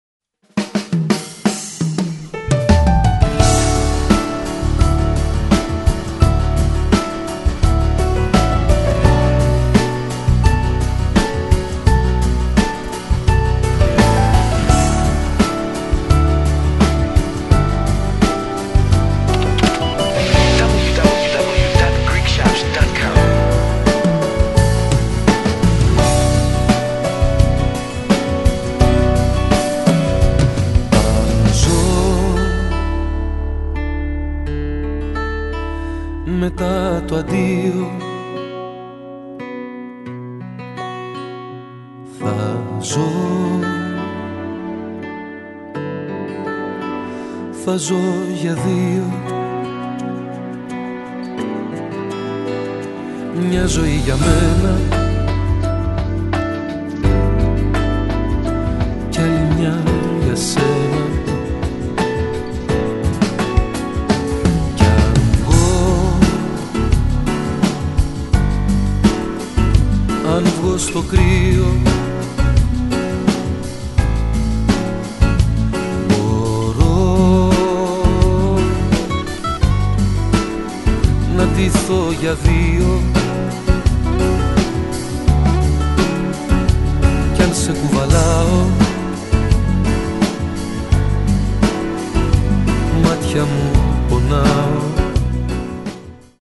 modern elafra